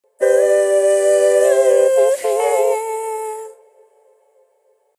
Original を Sonitus の５エフェクトを使って、イジクってみました。 EQ ・ Phase ・ Reverb ・ Delay ・ Multiband
Phase で ステレオ感を増し、各ボイスがよく分離したような・・・。